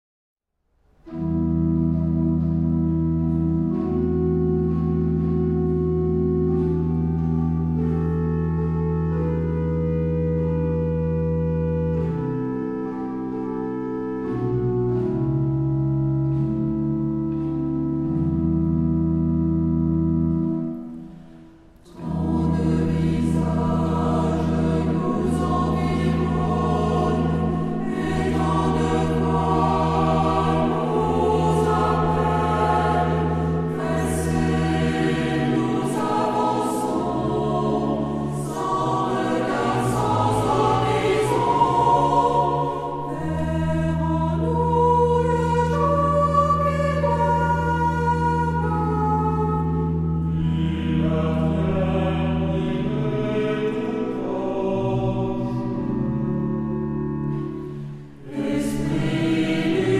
Género/Estilo/Forma: Cántico ; Sagrado
Carácter de la pieza : lento
Tipo de formación coral: SATB  (4 voces Coro mixto )
Instrumentos: Organo (1)
Tonalidad : mi menor